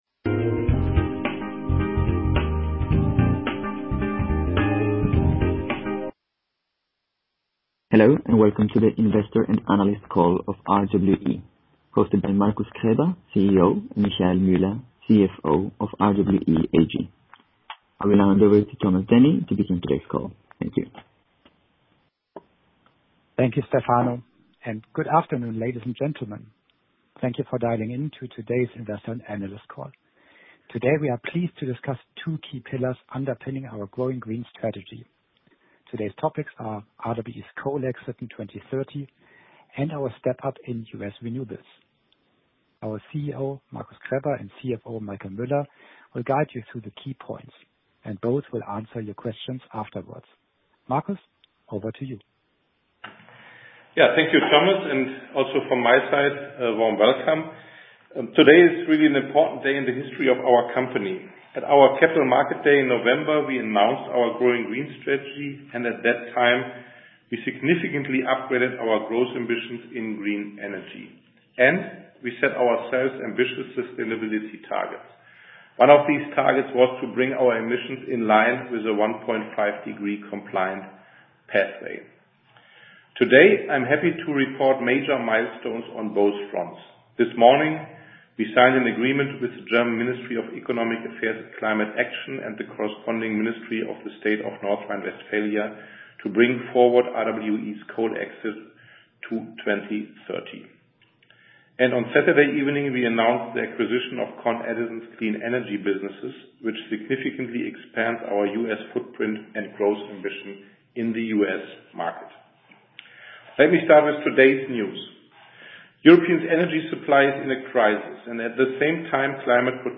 Investor and analyst conference call